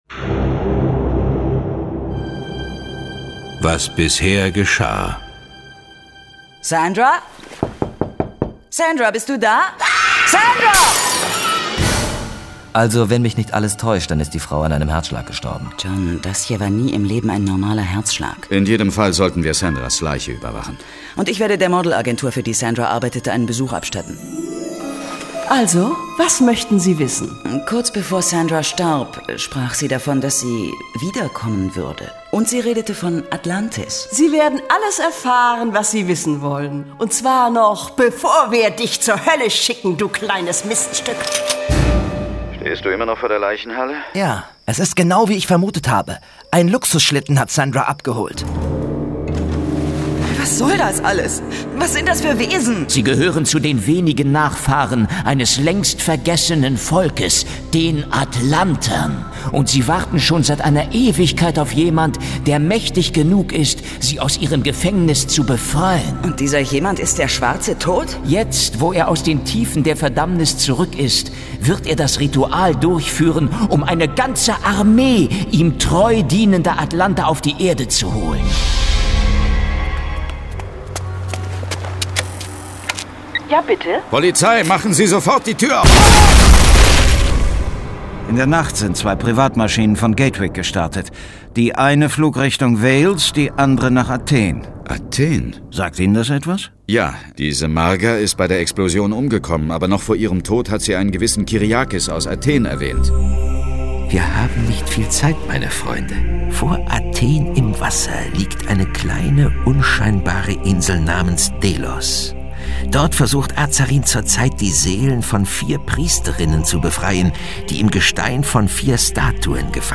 John Sinclair - Folge 9 Das Dämonenauge. Hörspiel.